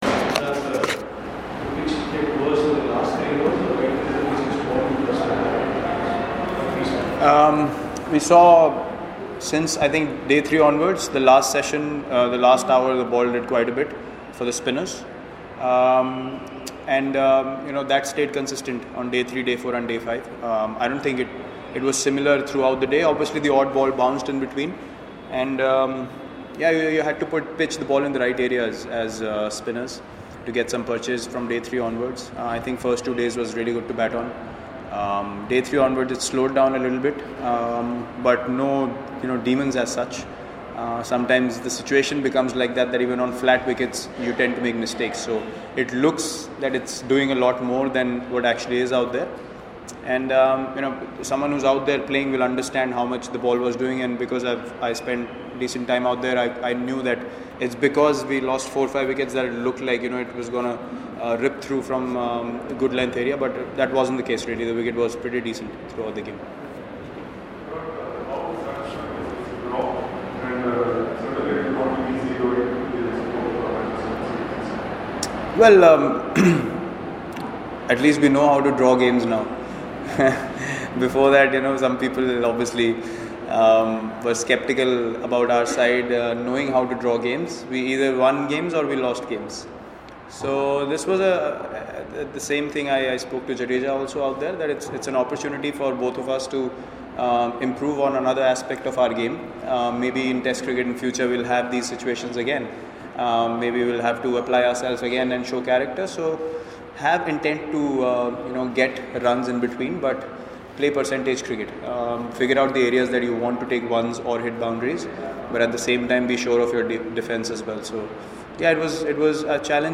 LISTEN: Skipper Virat Kohli Speaking After Drawn Rajkot Test